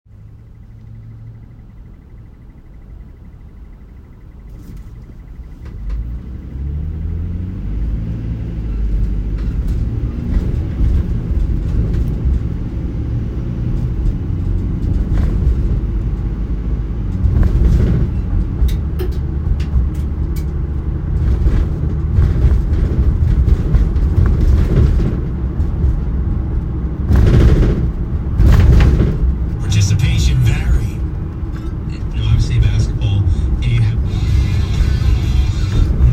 (Inside delivery van driving down Jericho Turnpike.)
-Van’s suspension working
-Van’s breaks squeaking
-The van’s engine revving up
-The wheels on the asphalt
-Small, unknown object bouncing around the back of the van
-A man’s voice on the radio
Field-Recording-2-1.m4a